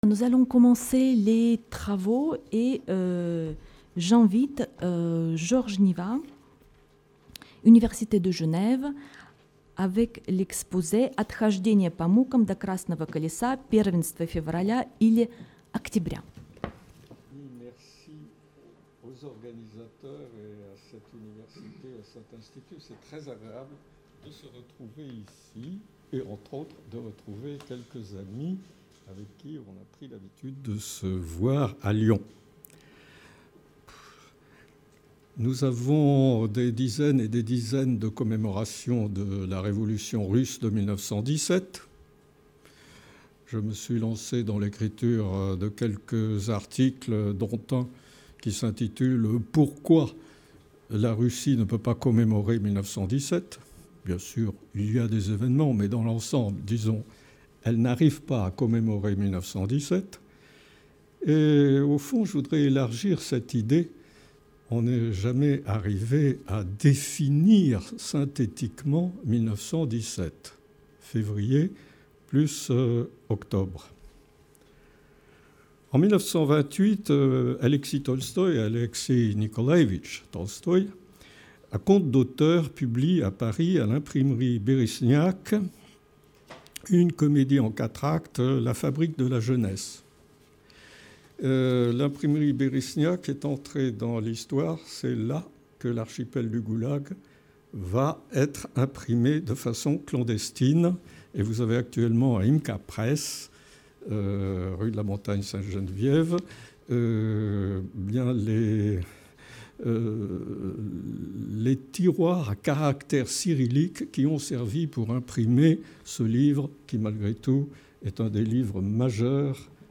Intervention Georges Nivat